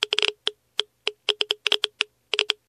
geiger_4.ogg